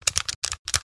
Download Free Gamepad Sound Effects | Gfx Sounds
Joystick-buttons-press-multiple-times-quick-4.mp3